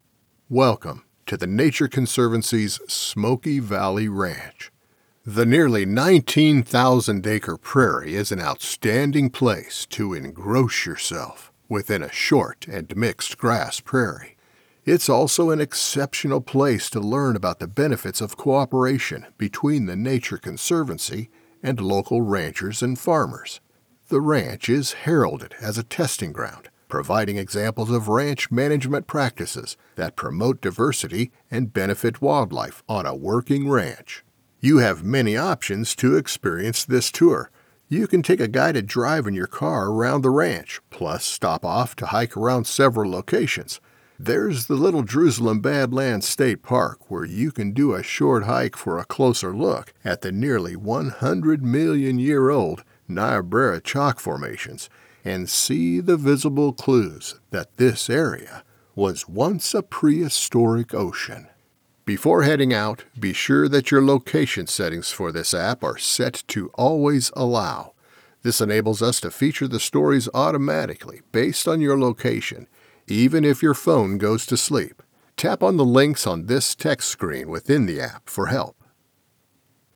Unique rich character, warm genuine comfort
Unique. Rich. Warm. Comforting.
Smoky Valley Ranch Audio Tour | Information
• Professional home studio & editing, based in Lincoln Nebraska